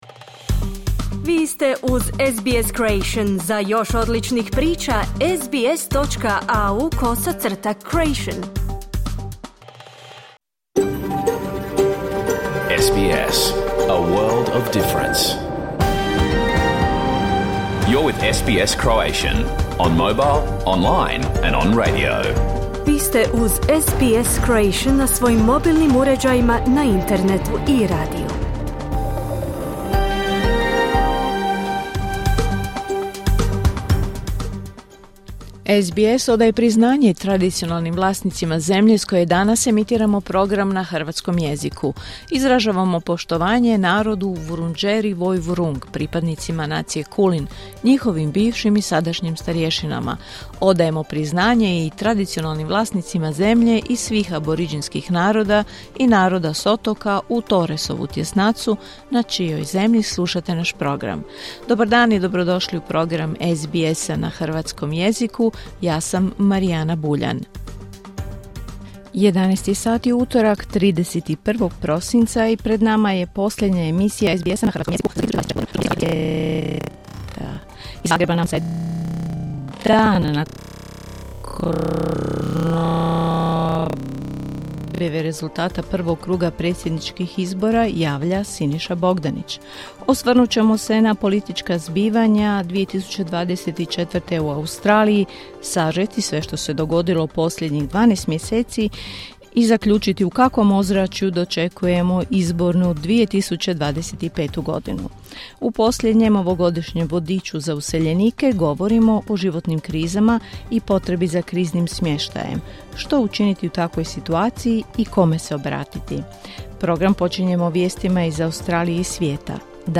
Vijesti i aktualne teme iz Australije, Hrvatske i svijeta. Emitirano uživo na radiju SBS1 u 11 sati, po istočnoaustralskom vremenu.